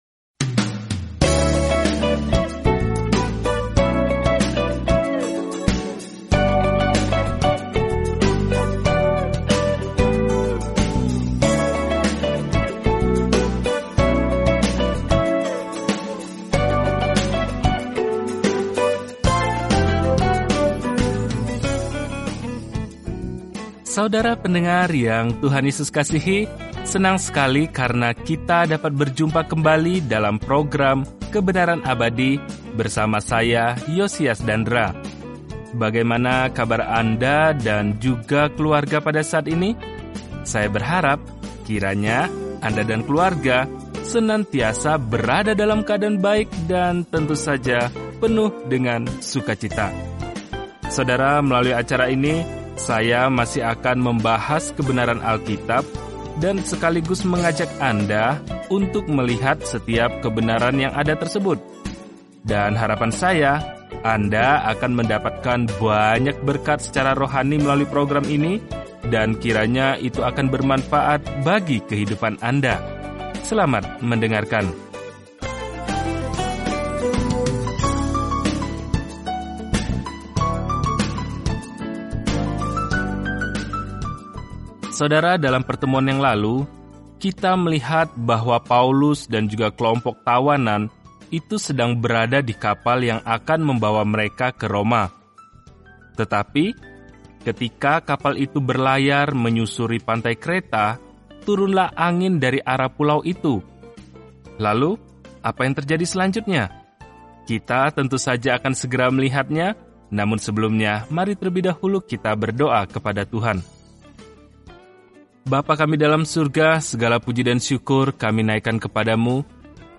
Jelajahi Kisah Para Rasul setiap hari sambil mendengarkan studi audio dan membaca ayat-ayat tertentu dari firman Tuhan.